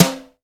Brush Sn3.wav